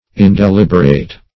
Indeliberate \In`de*lib"er*ate\, a. [L. indeliberatus.